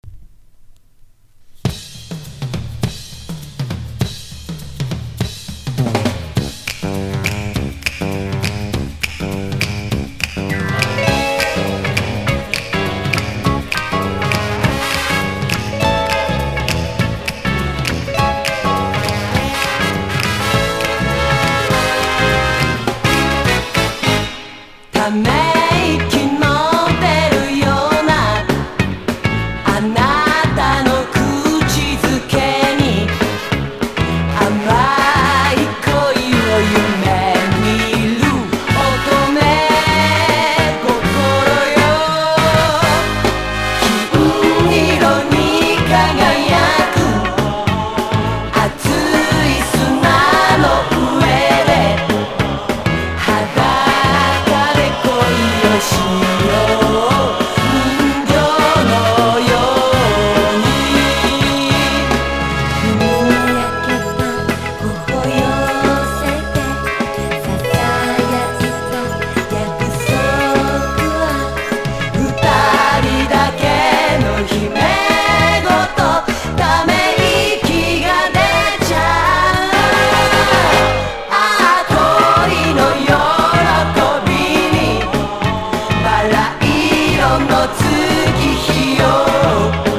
スウィンギンなナイス・カバー！
# 和モノ / ポピュラー